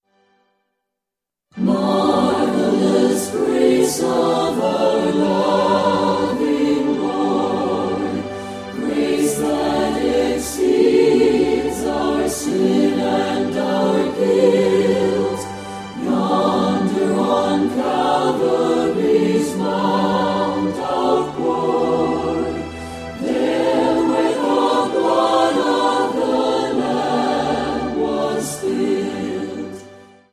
mit leichter instrumentaler begleitung
• Sachgebiet: Praise & Worship